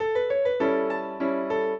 piano
minuet10-4.wav